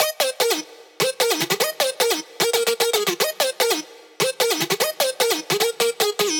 150BPM Lead 13 D#maj.wav